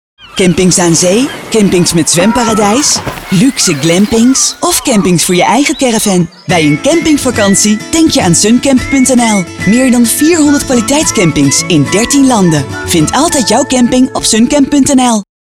Natural, Reliable, Warm, Accessible, Friendly
Commercial
A voice you believe in and that touches you.